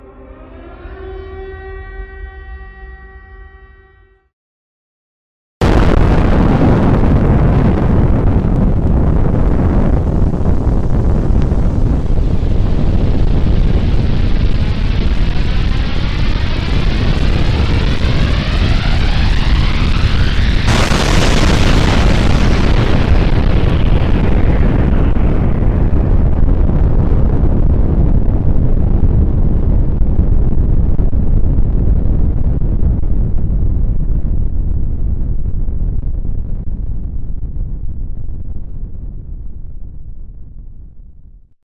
Suara bom nuklir
Kategori: Efek suara
Keterangan: Unduh suara ledakan bom nuklir secara gratis di sini.
suara-bom-nuklir-id-www_tiengdong_com.mp3